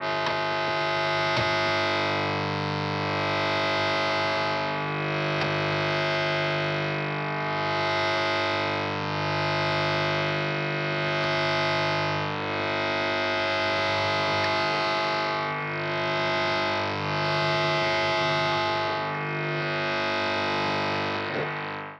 При записи соотв в микрофонный и в инструментальный входы этот вч писк попадает.
Но это не самое страшное, когда беру гитару, тут начинается настоящее мракобесие, приложил как она звучит если с ней крутиться на хайгейне.
Вложения HIGain_Noise.mp3 HIGain_Noise.mp3 689,8 KB · Просмотры: 1.284